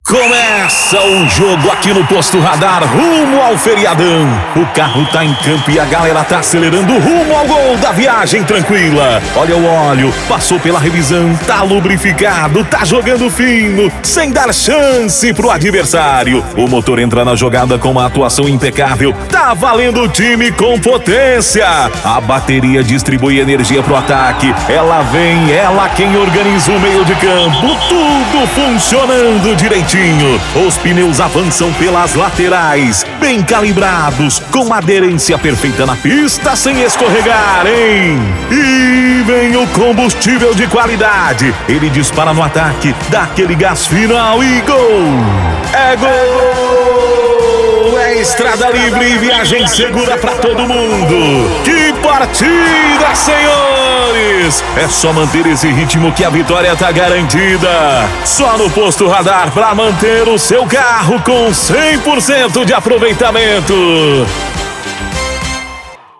ESTILO-CARICATO - FUTEBOL: